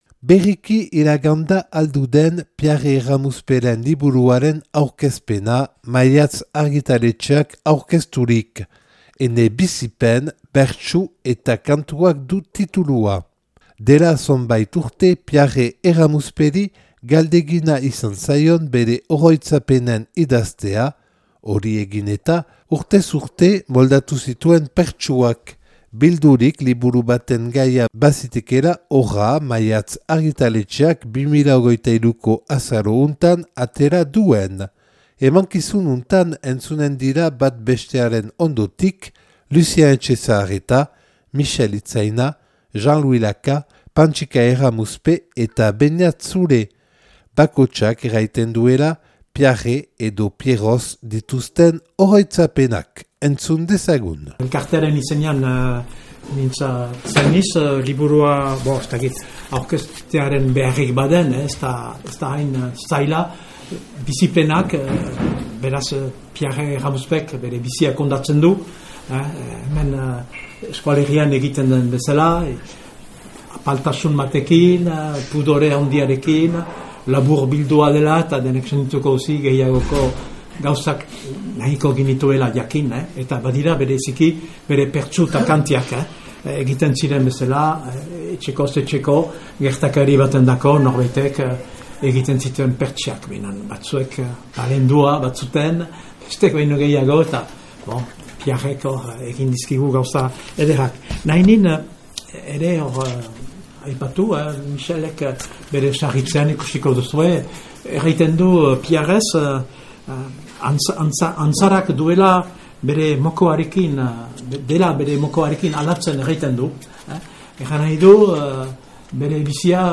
Liburuaren aurkezpena Alduden 2023. azaroaren 11an Maiatz argitaletexearekin.